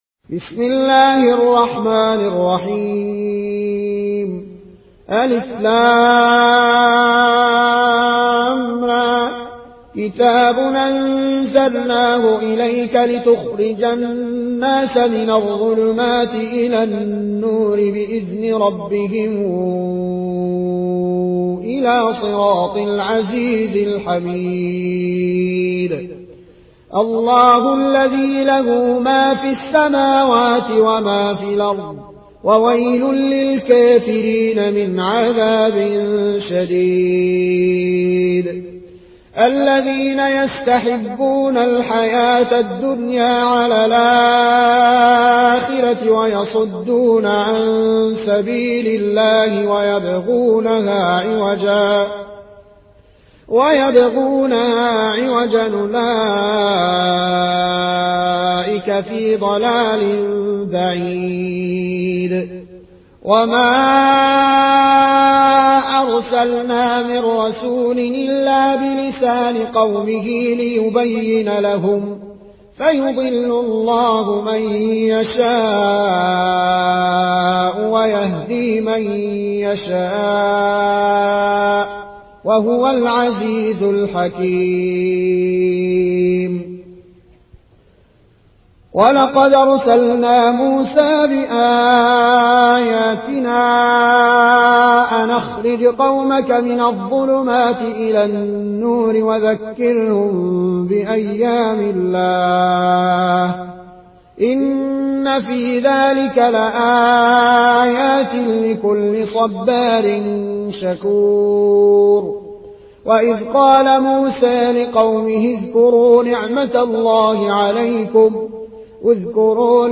(روایت ورش)